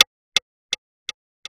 MagicCity Perc 11.wav